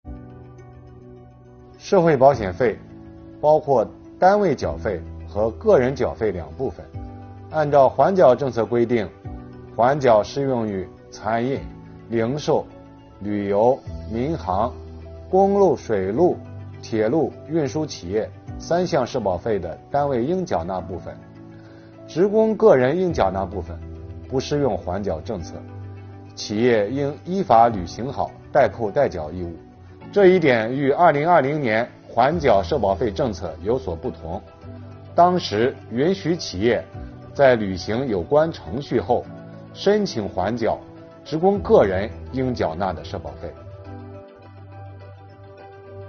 本期课程由国家税务总局社会保险费司副司长王发运担任主讲人，对公众关注的特困行业阶段性缓缴企业社保费政策问题进行讲解。